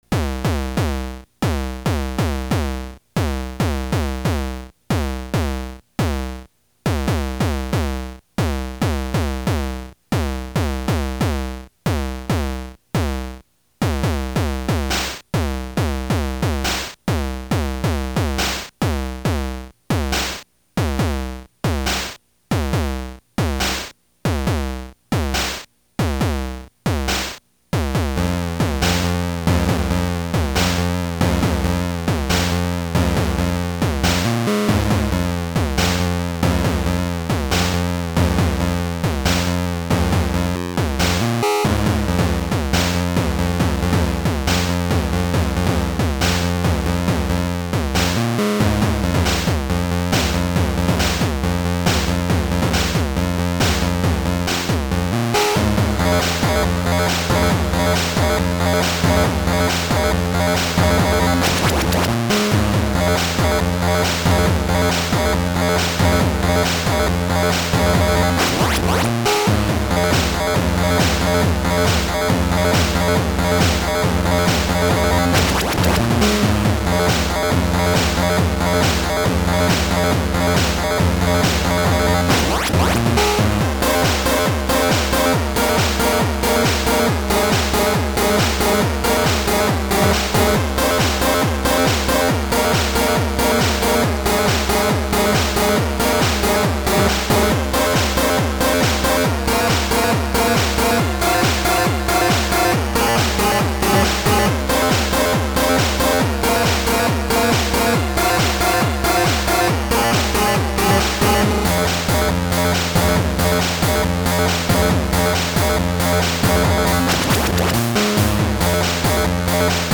DataBus 1.1.4 (LSDJ